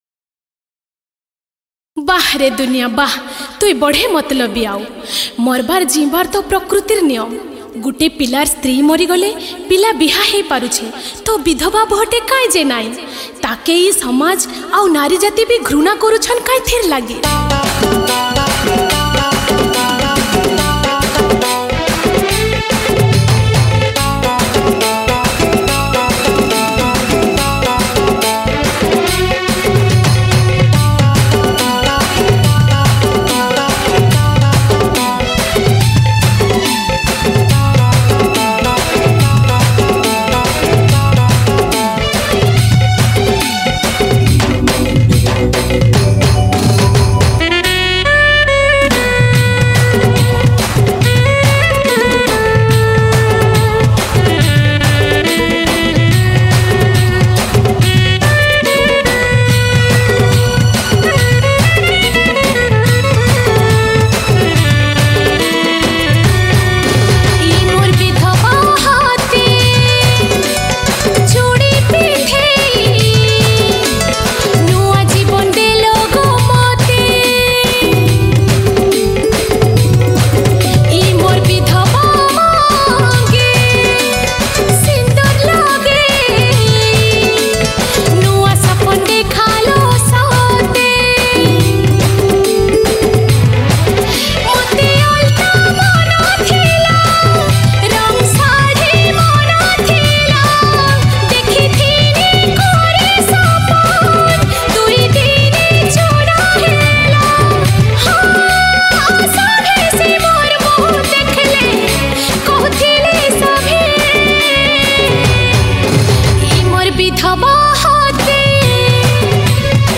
Sambalpuri Sad Song